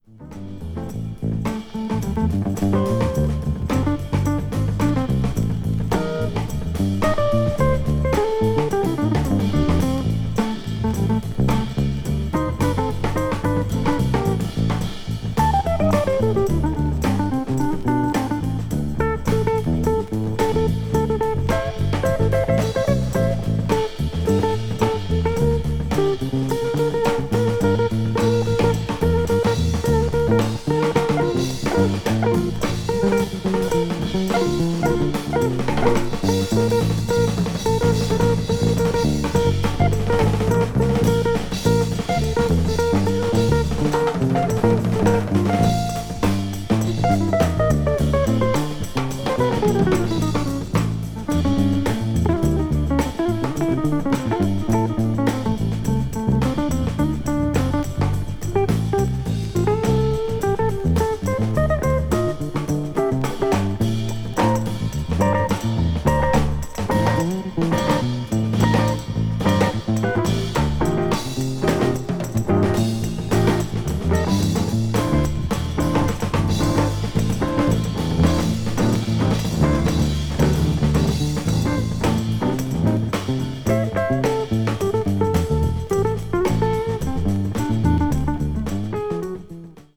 わずかにチリノイズが入る箇所あり
とのクインテット。